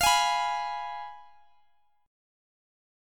Listen to Fdim strummed